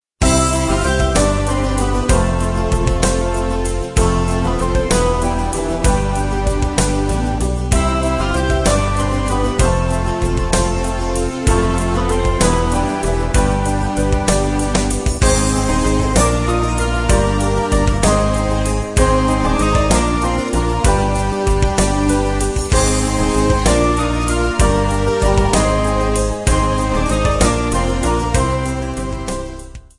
Polish Christmas Carols